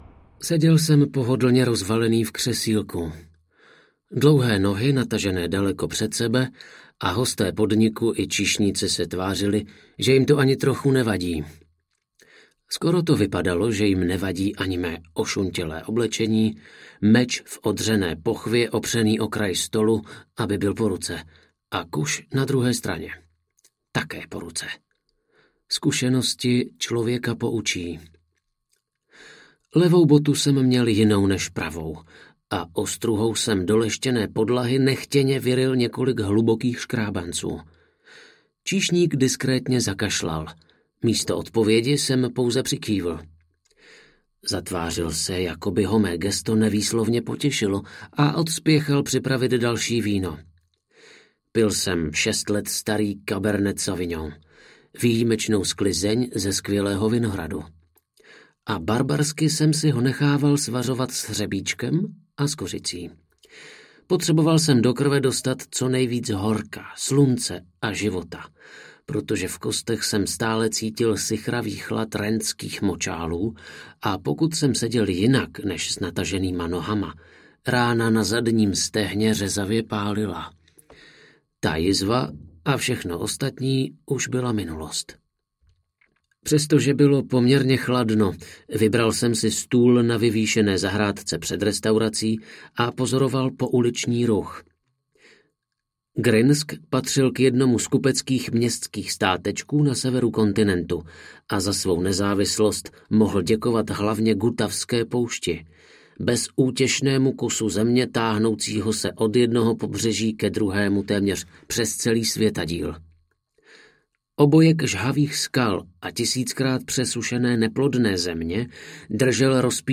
Konec vlka samotáře audiokniha
Ukázka z knihy